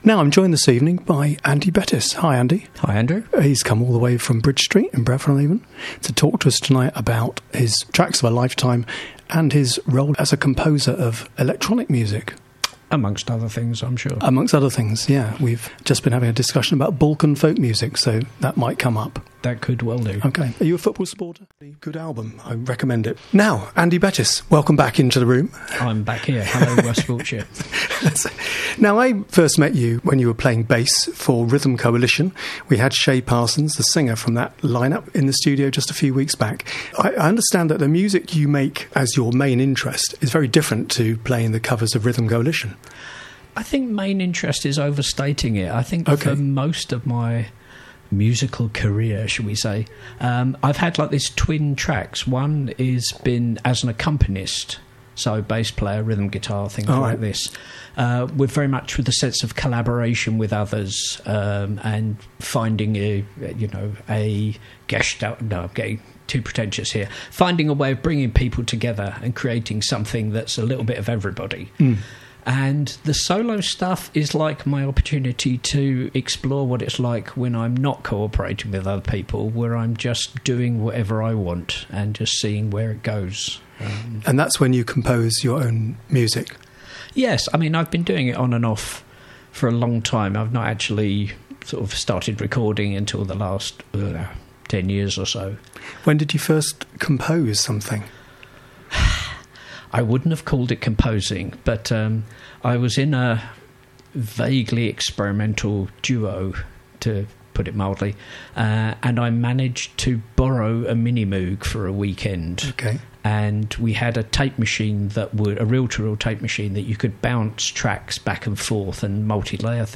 Here's the interview: West Wilts Radio interview